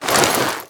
gear_rustle
tac_gear_6.ogg